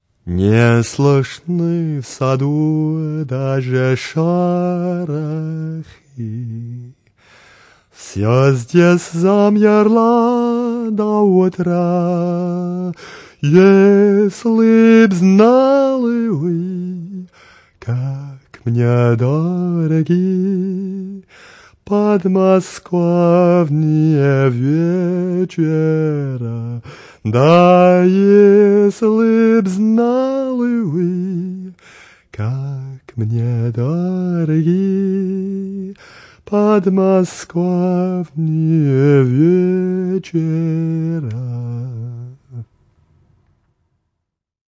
Russian Song I: